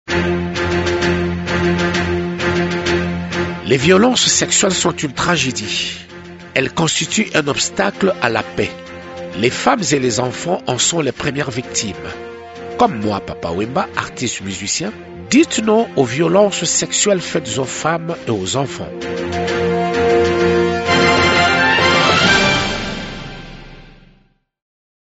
Ecoutez ici les messages de Papa Wemba, chanteur et leader d’opinion congolais, à l’occasion de la campagne de lutte contre les violences faites aux femmes et aux enfants: